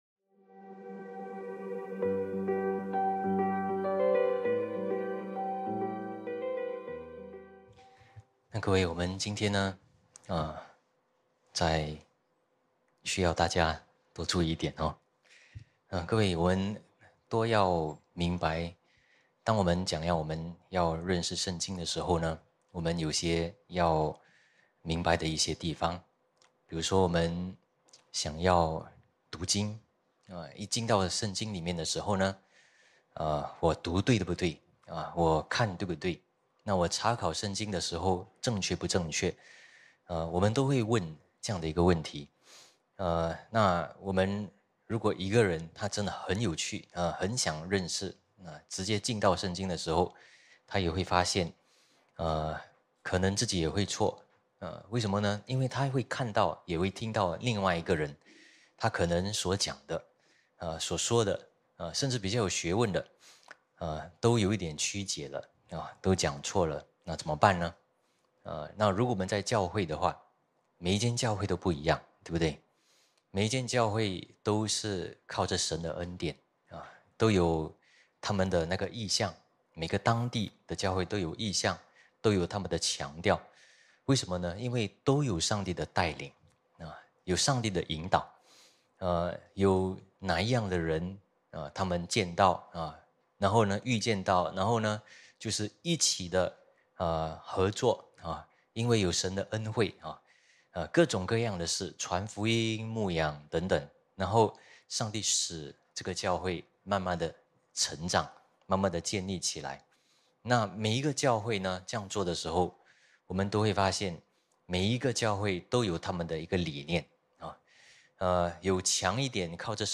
主日信息